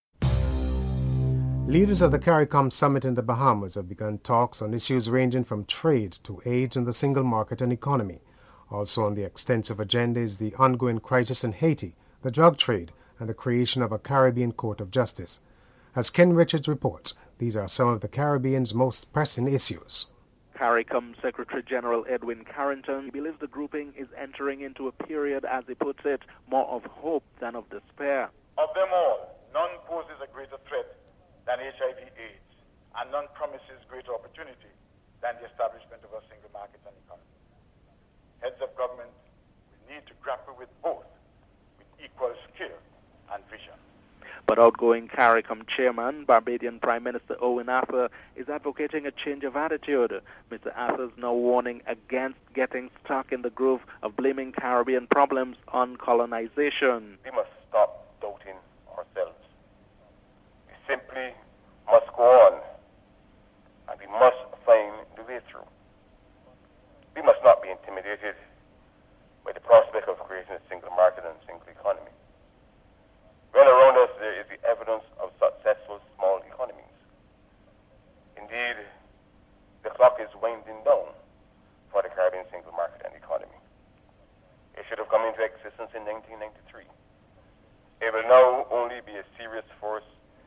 Prime Minister Owen Arthur, Incoming Chairman Hubert Ingraham and Vincentian Leader Ralph Gonsalves are interviewed.
Commonwealth Secretary General Don McKinnon is interviewed (08:57-10:23)